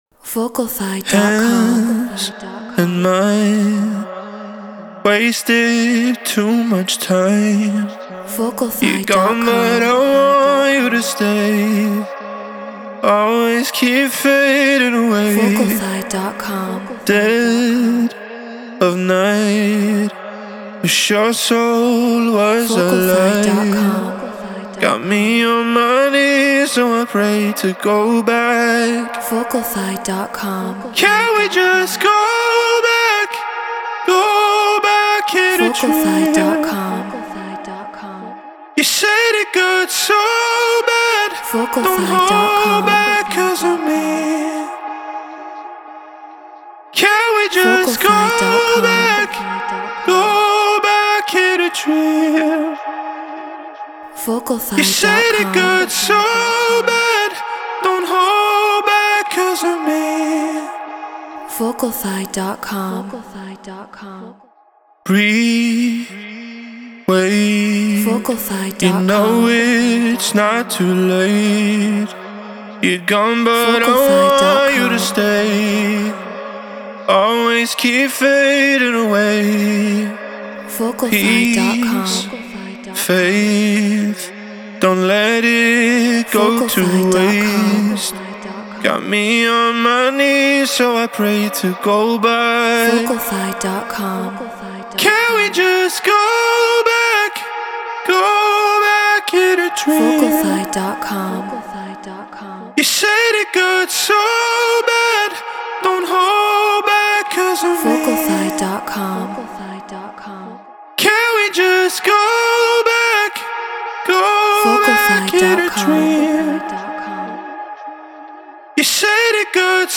House 120 BPM D#min
Shure SM7B Apollo Twin X Logic Pro Treated Room